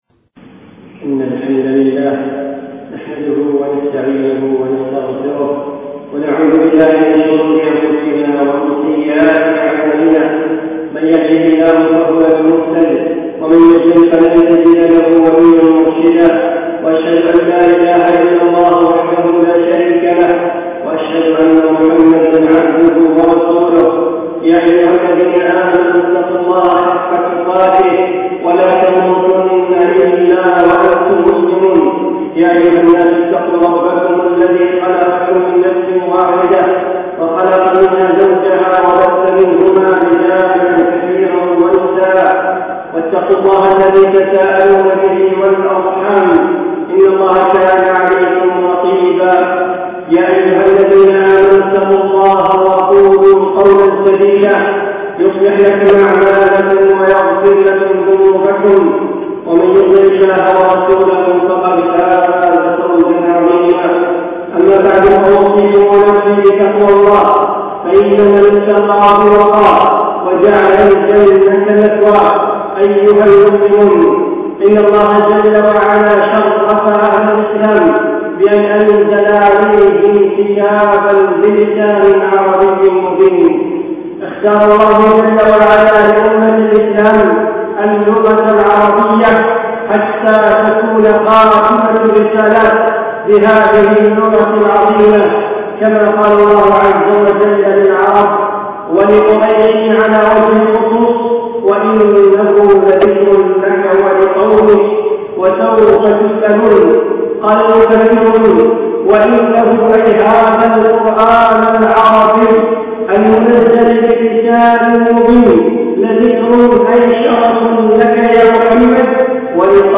خطب الشيخ في دولة الإمارات